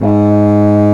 WND CONBSO08.wav